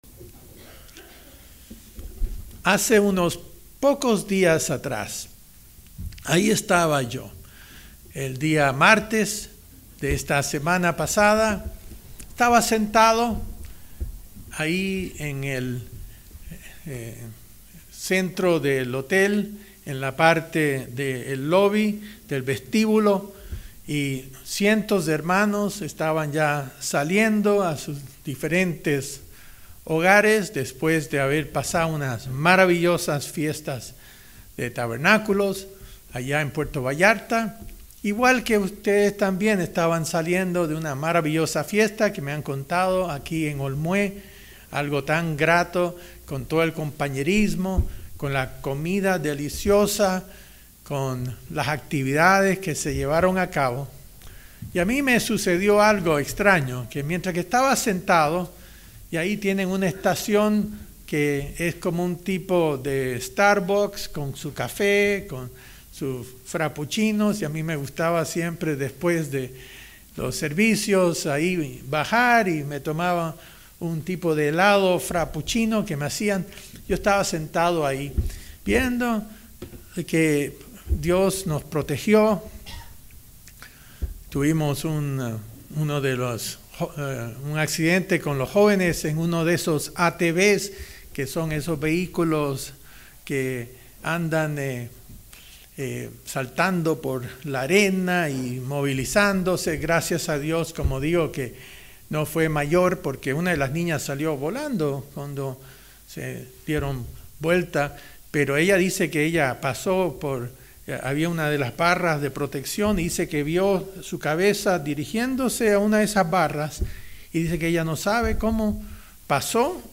Pese a todo el lujo y bendiciones físicas que puede uno atestiguar en el mundo, habrá algo mucho mejor en el futuro próximo, con un valor superior. Mensaje entregado el 26 de octubre de 2019.